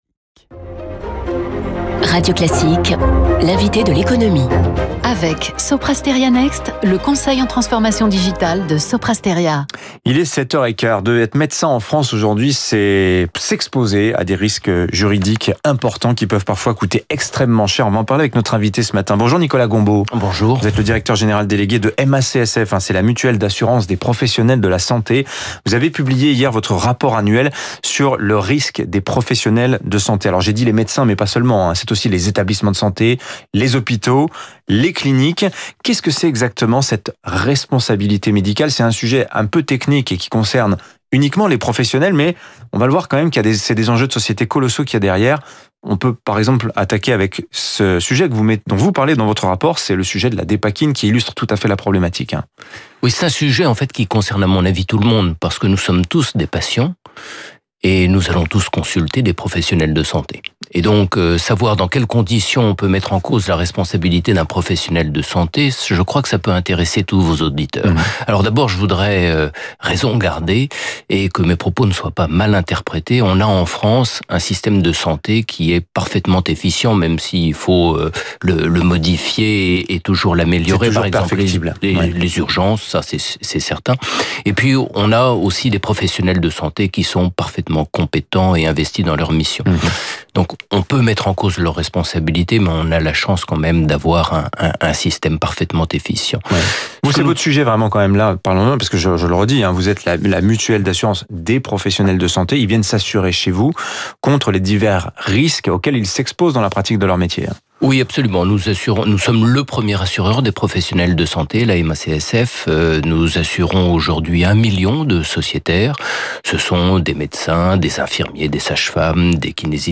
Les erreurs médicales - Reportage Arte - Vox Pop